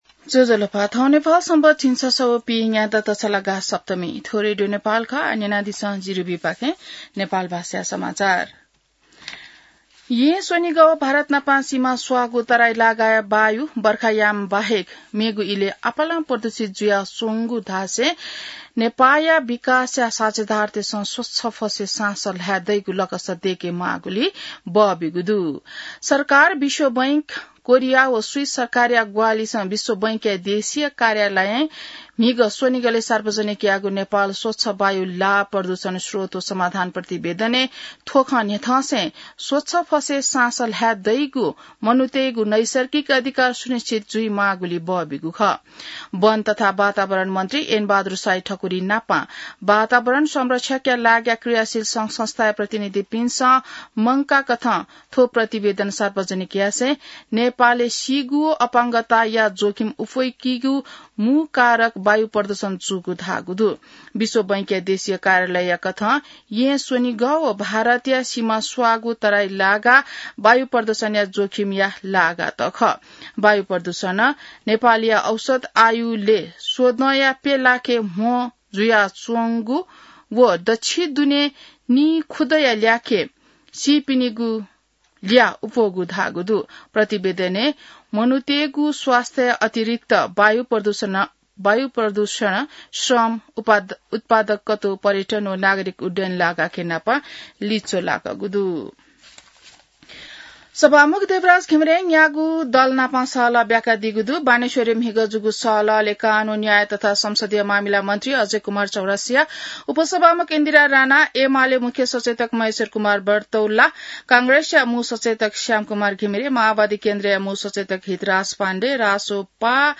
नेपाल भाषामा समाचार : ४ असार , २०८२